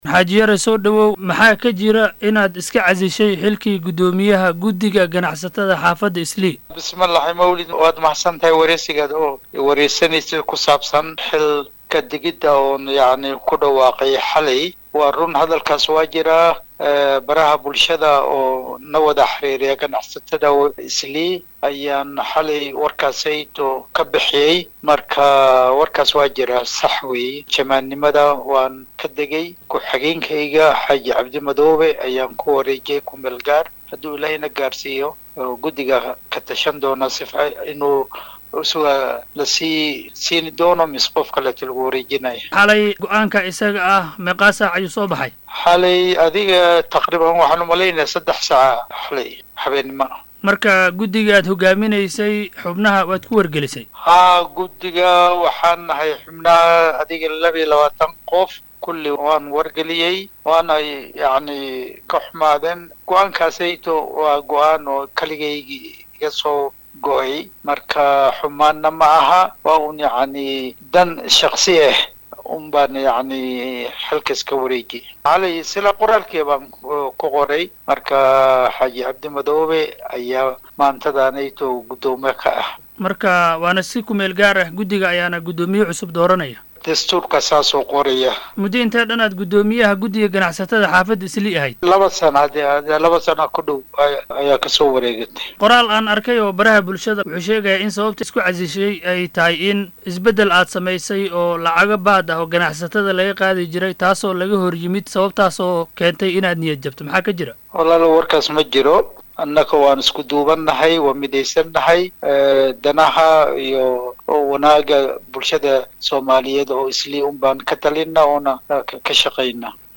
wareysi gaar ah